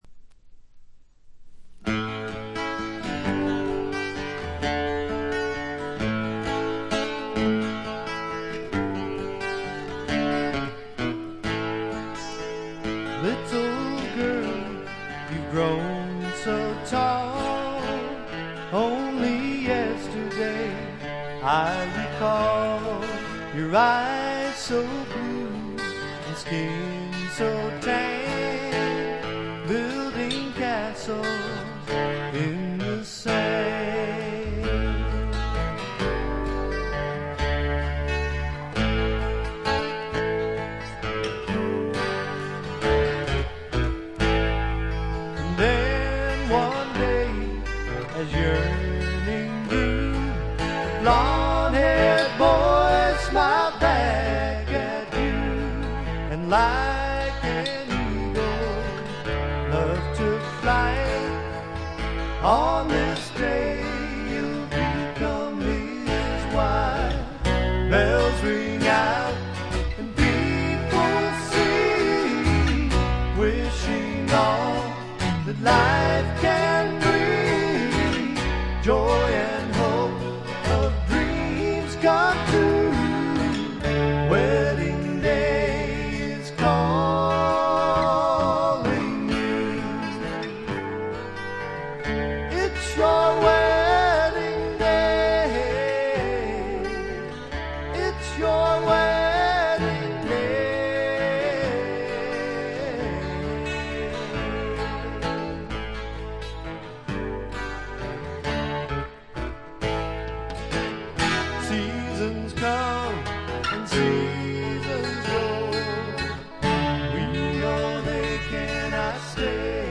ほとんどノイズ感無し。
サンディエゴのシンガー・ソングライターによる自主制作盤。
試聴曲は現品からの取り込み音源です。